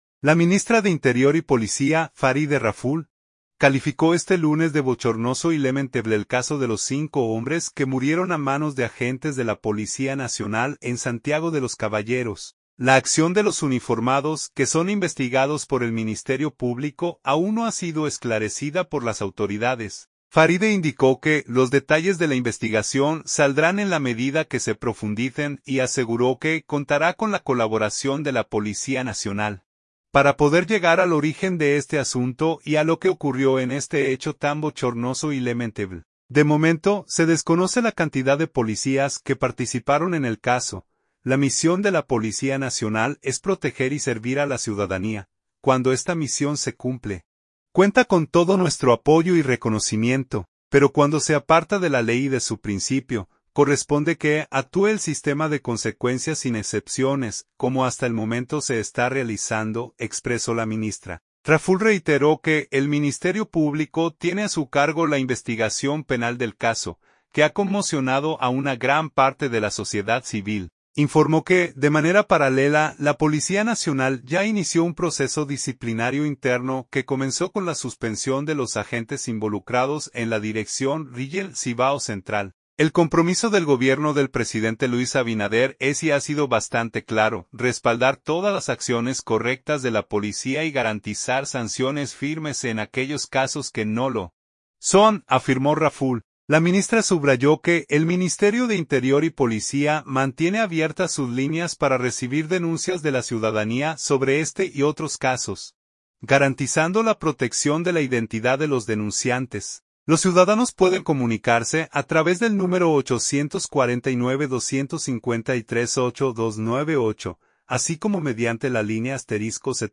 La ministra habló del tema con periodistas durante una rueda de prensa desde el Palacio de la Policía Nacional.